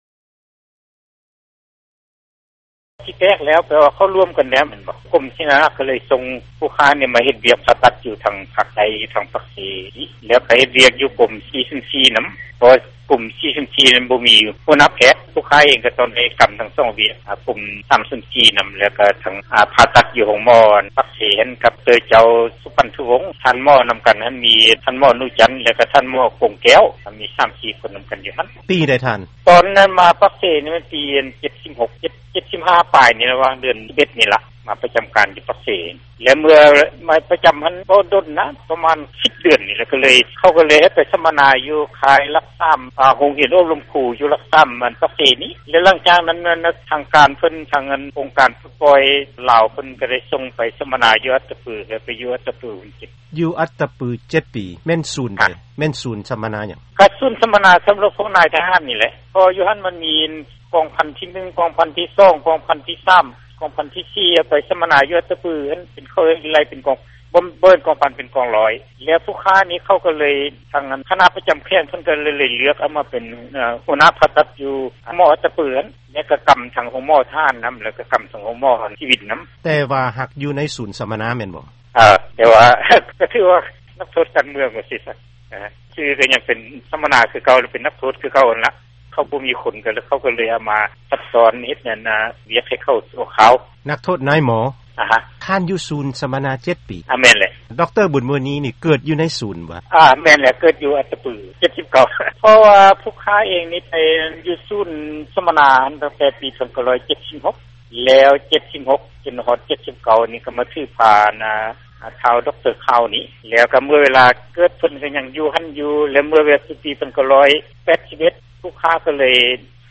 ລັດ ແລະຜົນສໍາເລັດຂອງພວກລູກເຕົ້າທາງດ້ານການສືກສາ ດັ່ງໃນບົດສໍາພາດຕໍ່ໄປນີ້: